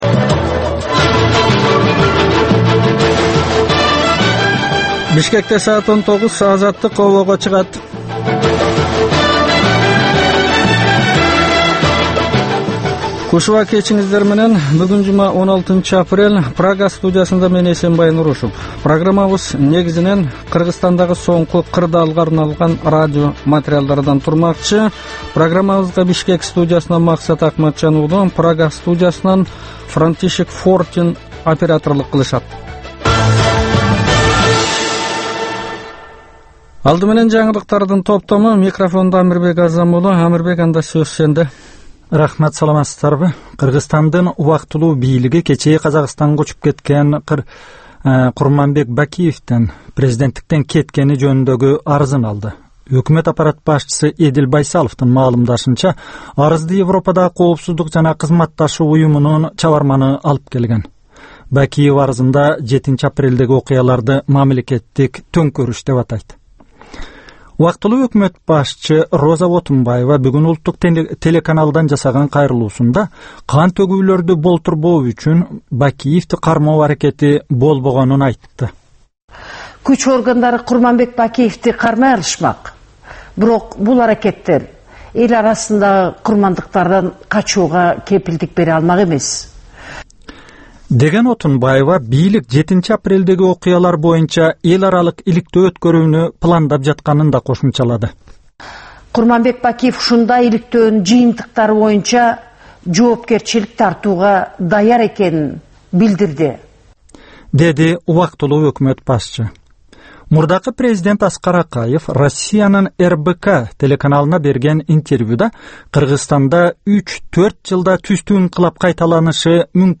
"Азаттык үналгысынын" бул кечки алгачкы берүүсү (кайталоо) жергиликтүү жана эл аралык кабарлардан, репортаж, маек, баян жана башка берүүлөрдөн турат.